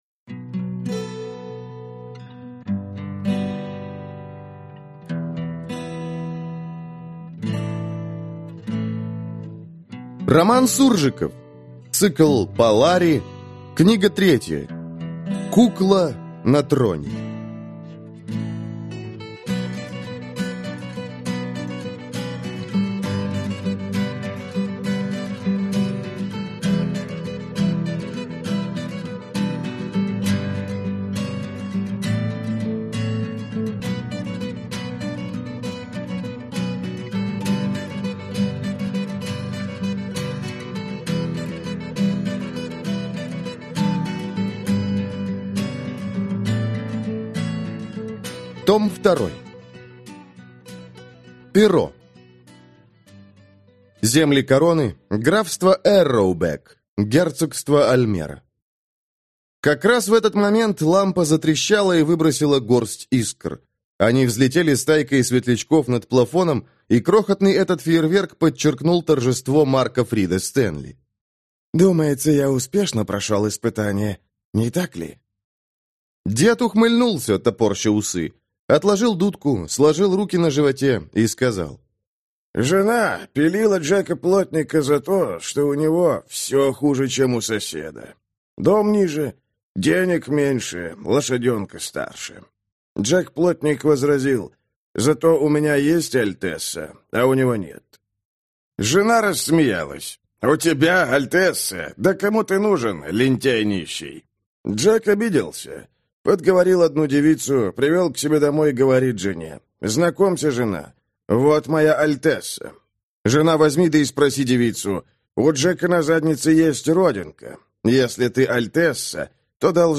Аудиокнига Кукла на троне. Том II | Библиотека аудиокниг